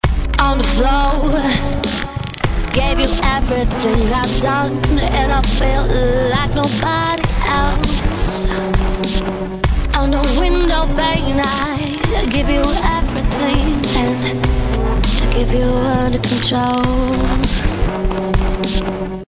Hier habe das Tempo auf 100 BPM eingestellt